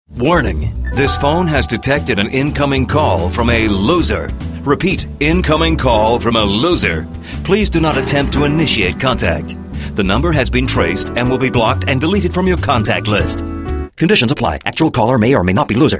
جلوه های صوتی
SMS Tones